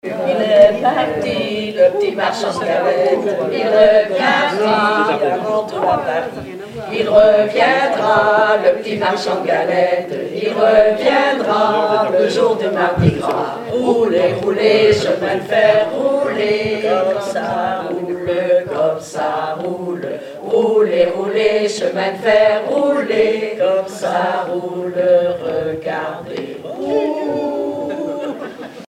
L'enfance - Enfantines - rondes et jeux
formulette enfantine : amusette
Chansons, formulettes enfantines
Pièce musicale inédite